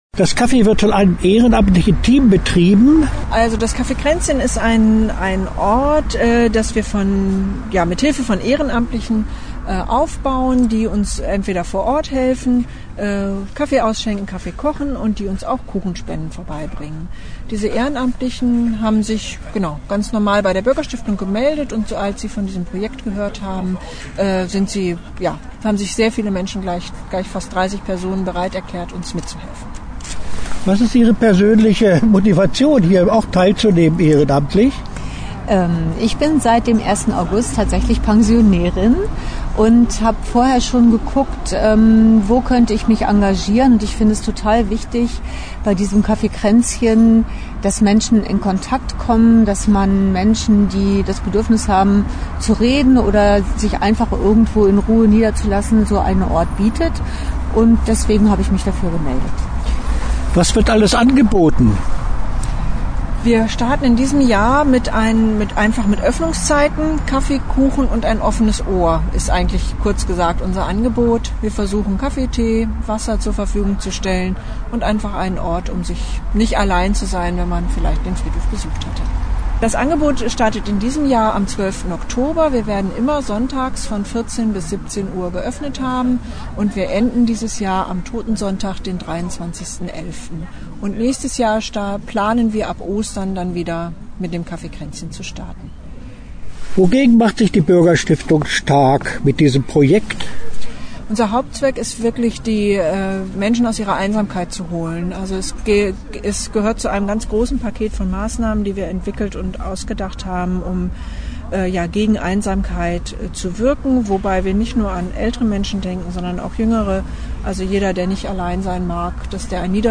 Interview-Cafe-Kraenzchen.mp3